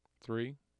AIF tracks and Null tracks of speech utterance “